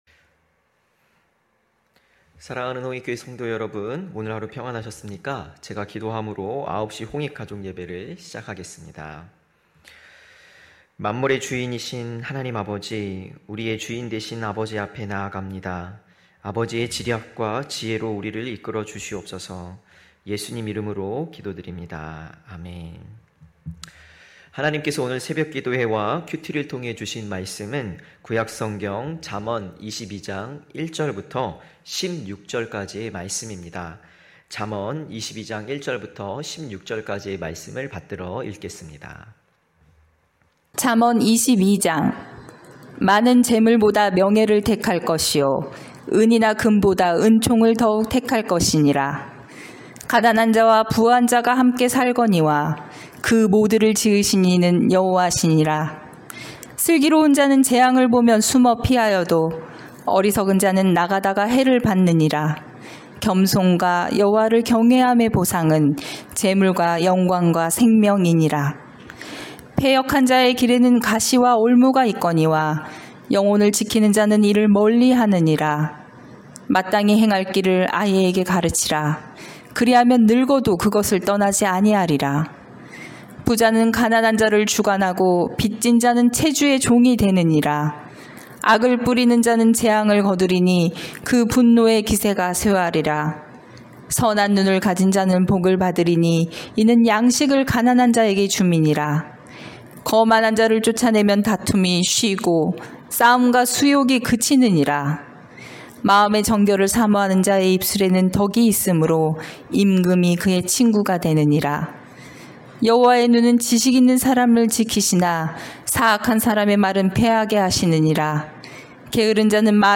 9시홍익가족예배(6월11일).mp3